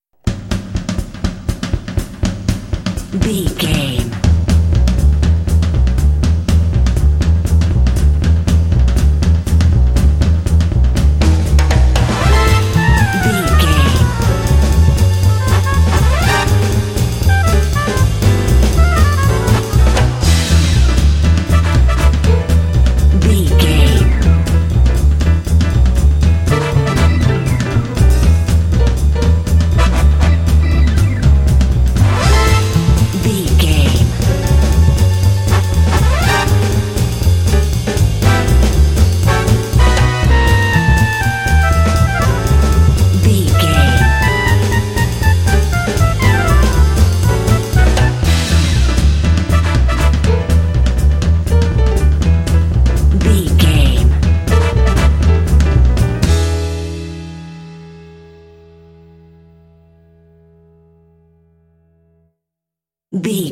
Uplifting
Aeolian/Minor
Fast
energetic
lively
cheerful/happy
drums
double bass
piano
electric organ
brass
big band
jazz